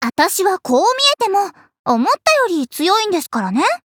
文件 文件历史 文件用途 全域文件用途 Ja_Fifi_tk_03.ogg （Ogg Vorbis声音文件，长度3.9秒，100 kbps，文件大小：47 KB） 源地址:游戏语音 文件历史 点击某个日期/时间查看对应时刻的文件。 日期/时间 缩略图 大小 用户 备注 当前 2018年5月25日 (五) 02:13 3.9秒 （47 KB） 地下城与勇士  （ 留言 | 贡献 ） 分类:祈求者比比 分类:地下城与勇士 源地址:游戏语音 您不可以覆盖此文件。